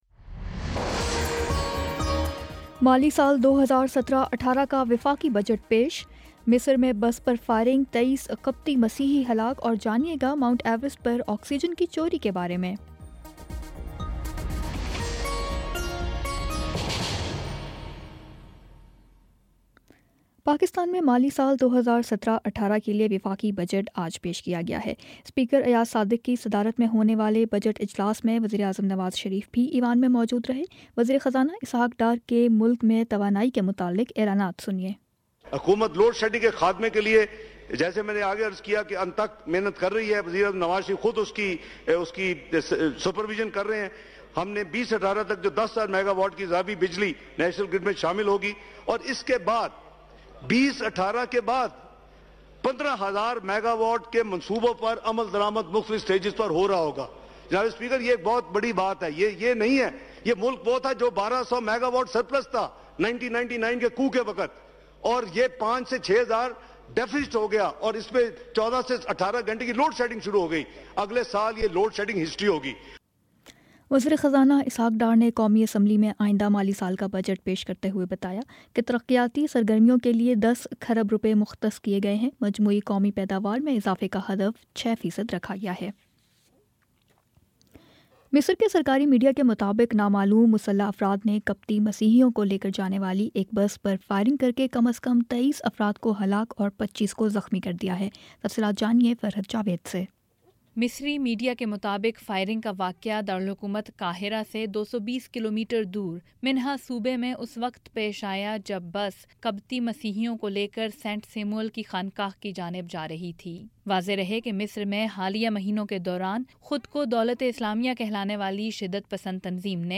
مئی 26 : شام سات بجے کا نیوز بُلیٹن